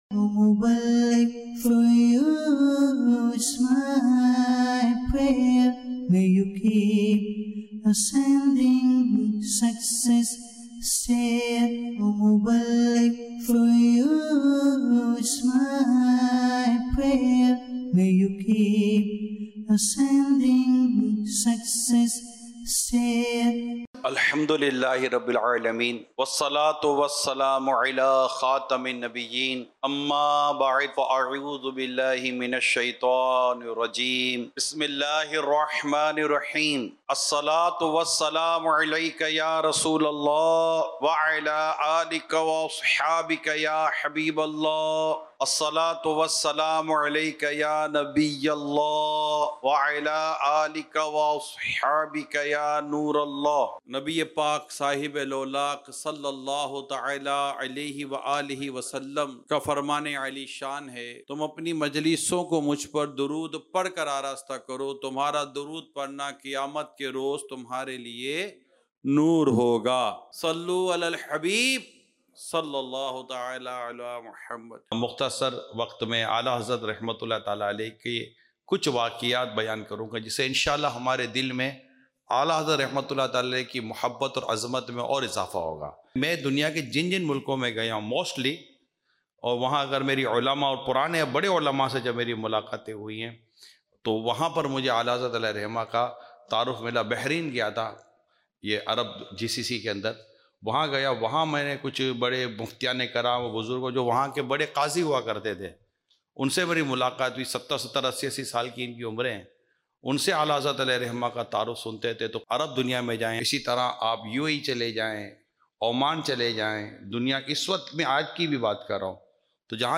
Sunnah Inspired Bayan - Aala Hazrat